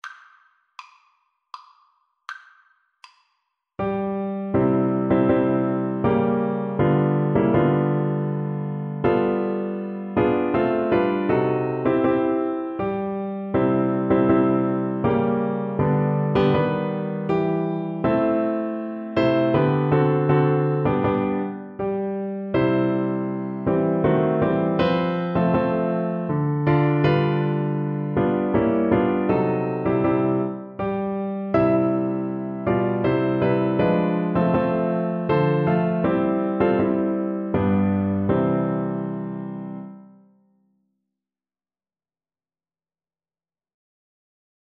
Cello
3/4 (View more 3/4 Music)
C major (Sounding Pitch) (View more C major Music for Cello )
Andante
Traditional (View more Traditional Cello Music)